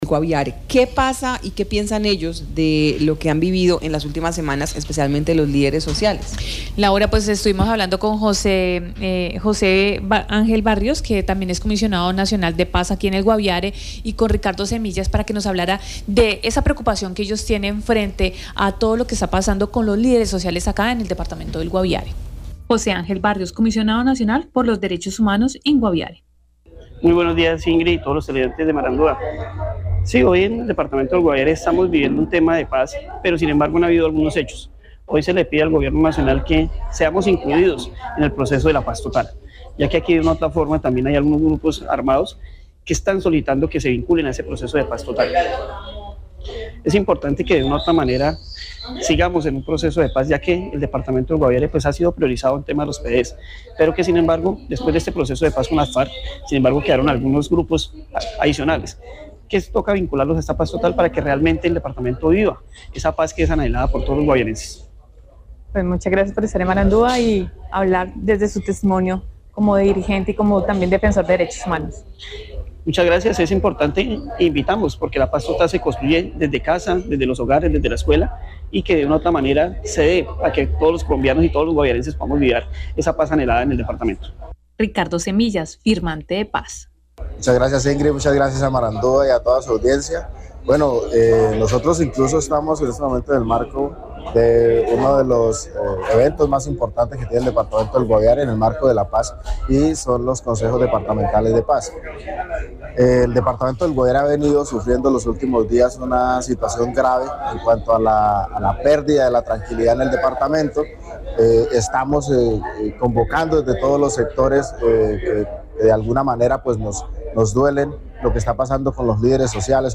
Concierto en memoria de líderes sociales desaparecidos en Guaviare